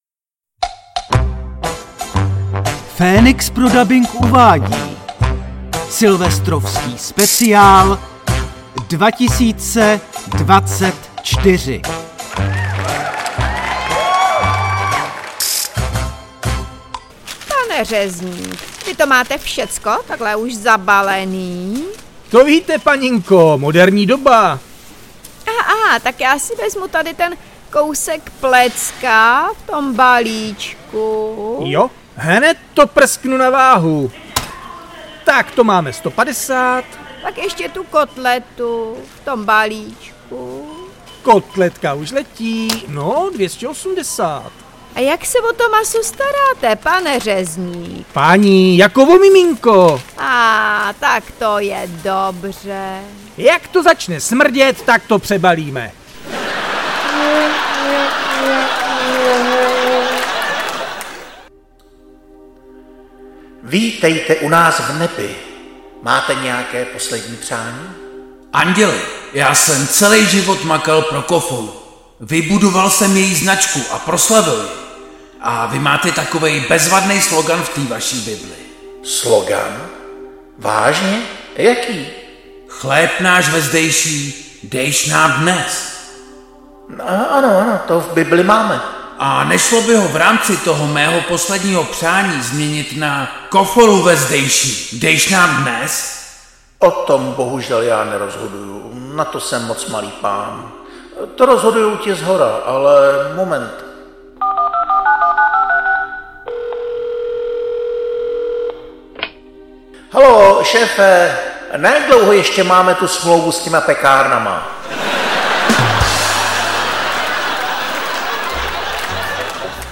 Zde Vám nabízíme vtipy, které jsme vám namluvili a zabalili do balíků.